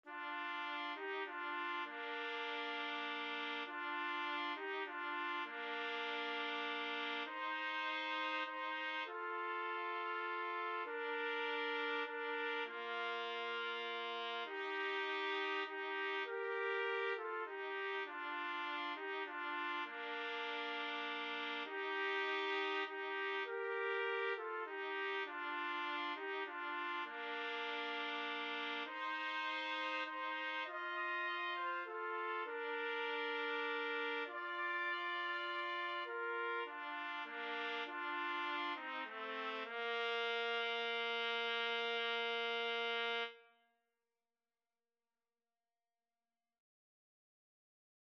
is a popular Christmas carol
6/8 (View more 6/8 Music)
Trumpet Duet  (View more Intermediate Trumpet Duet Music)